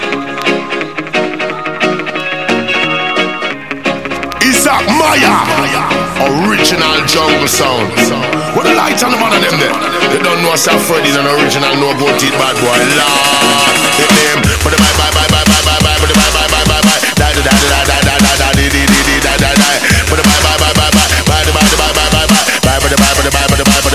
TOP >Vinyl >Drum & Bass / Jungle
TOP > Vocal Track
TOP > Jump Up / Drum Step